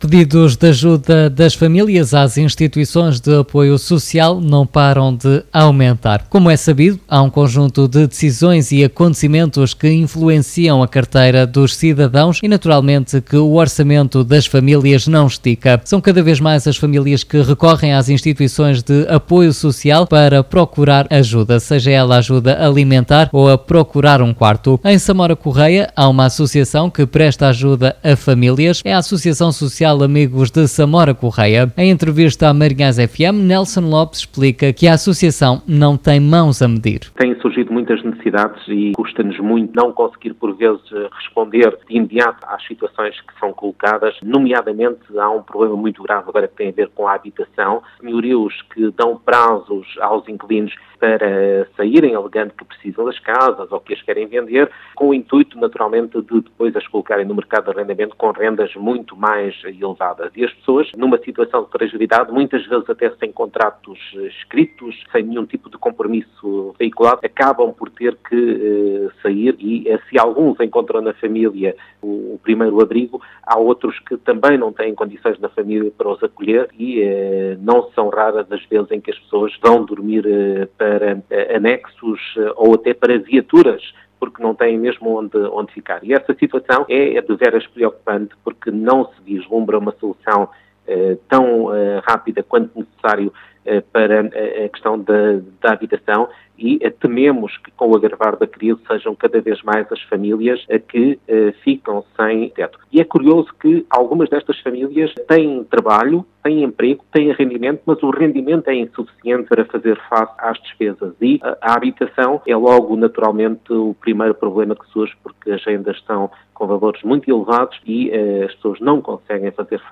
Em entrevista à MarinhaisFM